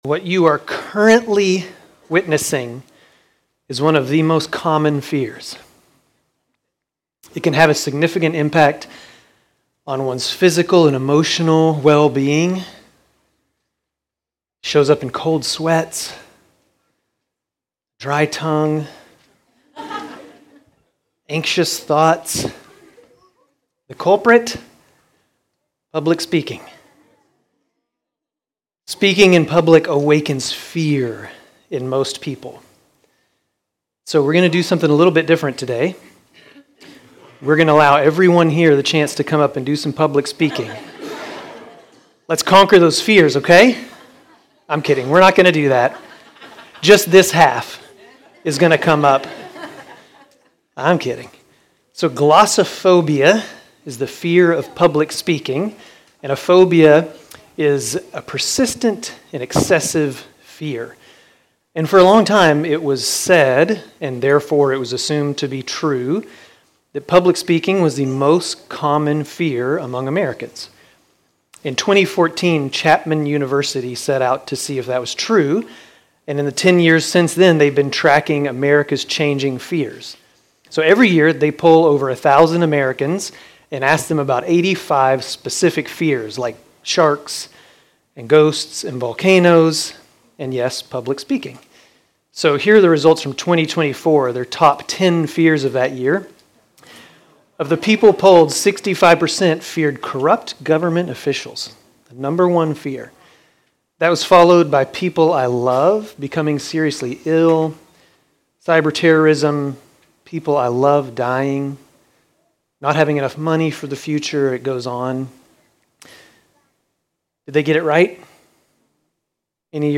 Grace Community Church Dover Campus Sermons 5_4 Dover Campus May 05 2025 | 00:26:17 1x 00:00 / 00:26:17 Subscribe Share RSS Feed Share Link Embed